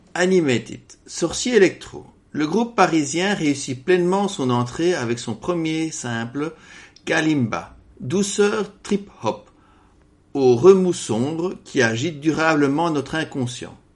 guimbarde, didgeridoo